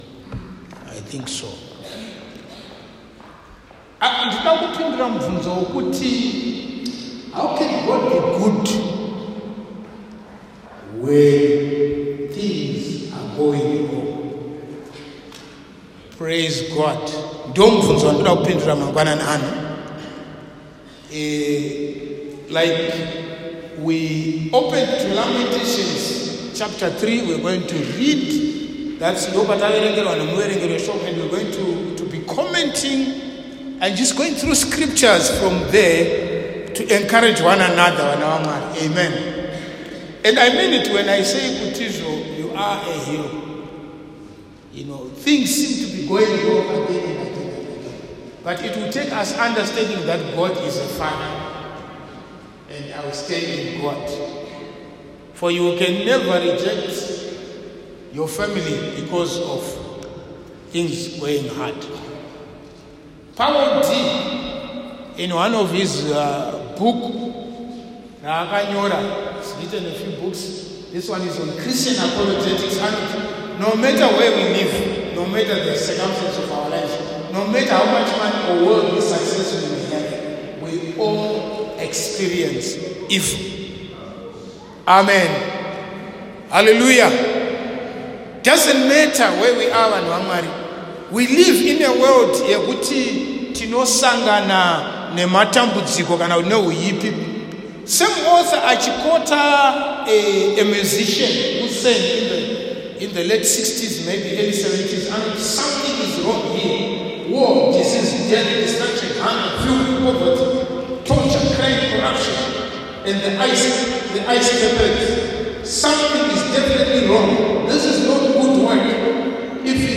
Sermons | Barnabas Leadership Outreach Center